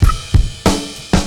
Organbeat 934bpm.wav